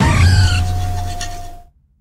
Cri de Flotte-Mèche dans Pokémon HOME.